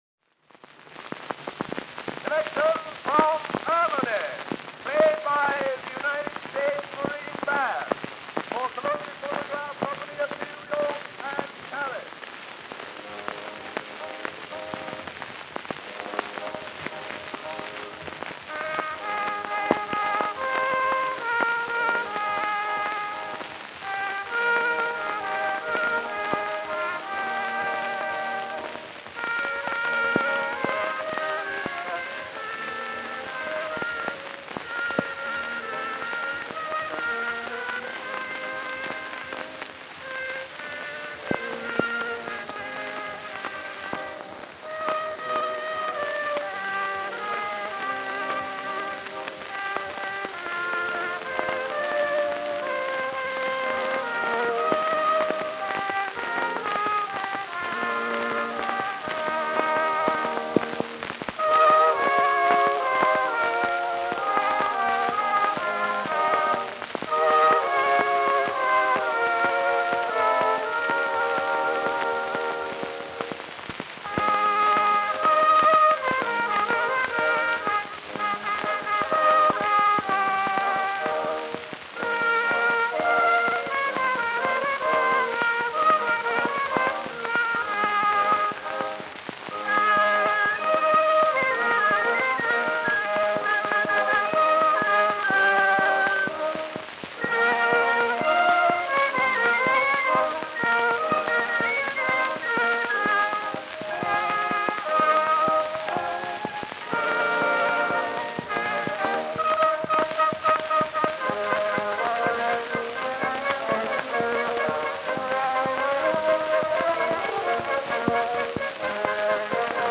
Disc 1: Early Acoustic Recordings